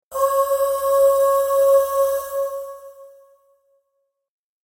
やわらかく優雅な女性のハミングが、通知を受け取る瞬間を心地よいものに変えます。